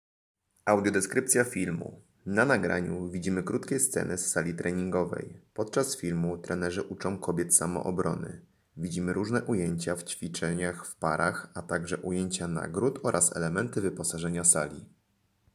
Nagranie audio Audiodeskrypcja_samoobrona.mp3